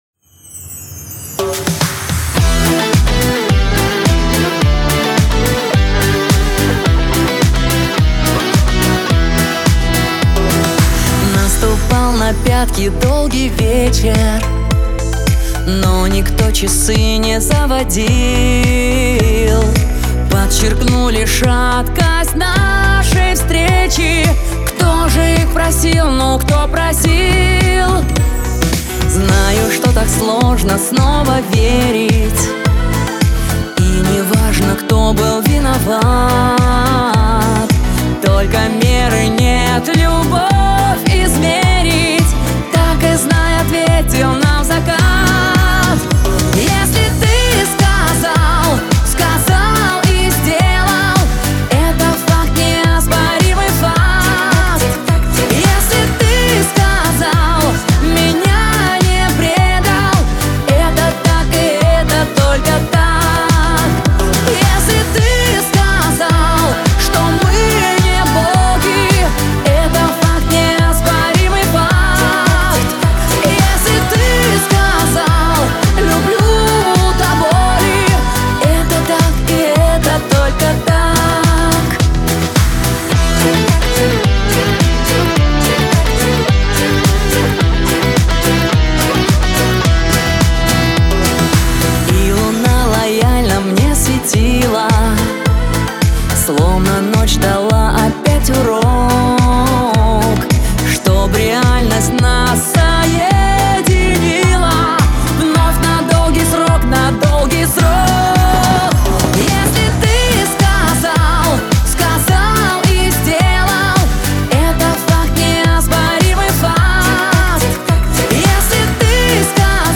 эстрада , pop , Лирика